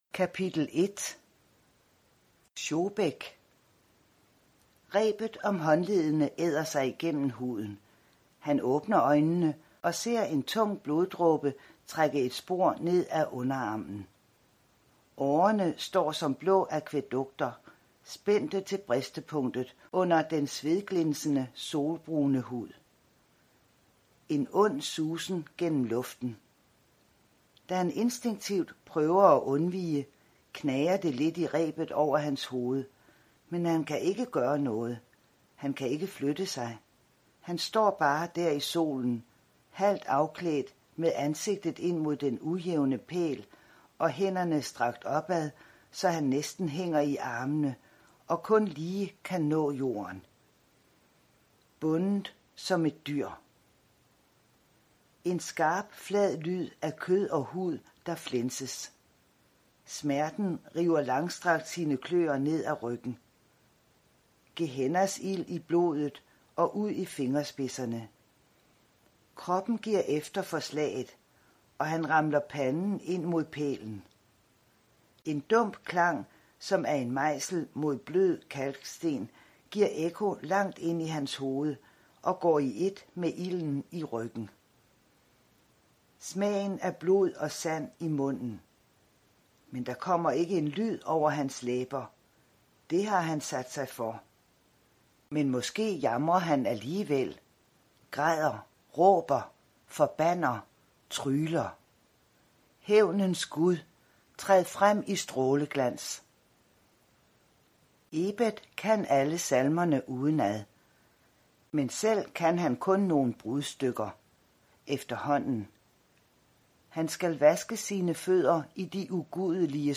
Hør et uddrag af Uden for byporten Uden for byporten Format MP3 Forfatter Anette Broberg Knudsen Bog Lydbog E-bog 149,95 kr.